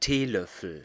Ääntäminen
Synonyymit Löffel Ääntäminen Tuntematon aksentti: IPA: /ˈteː.lœfl̩/ Haettu sana löytyi näillä lähdekielillä: saksa Käännös 1. cucharilla {f} 2. cucharada de té {f} 3. cucharadita {f} Artikkeli: der .